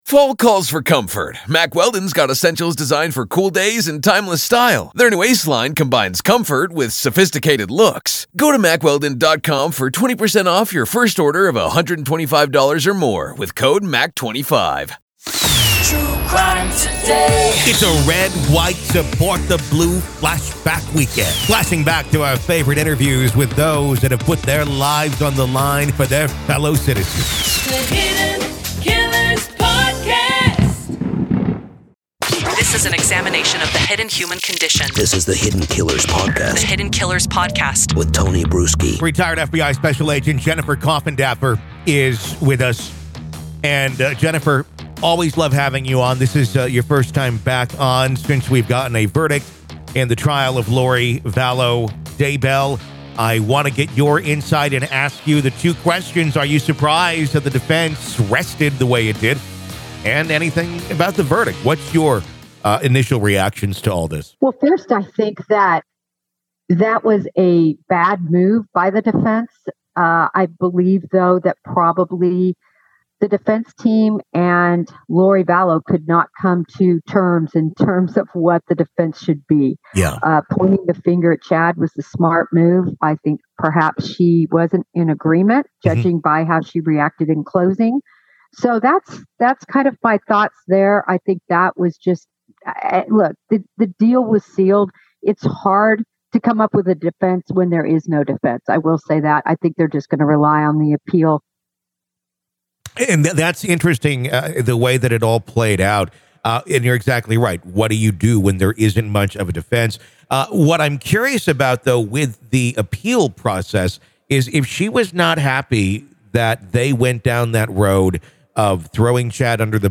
In the Red, White, Salute the Blue Flash-Back Weekend, we pay tribute to those who have dedicated their lives to law enforcement. This event celebrates our favorite interviews from the past, spotlighting the stories, experiences, and insights shared by our esteemed guests from...